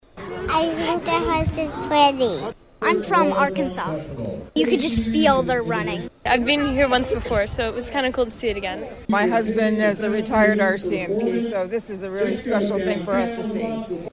Those precision drills wowed spectators at Donagan Park Saturday afternoon.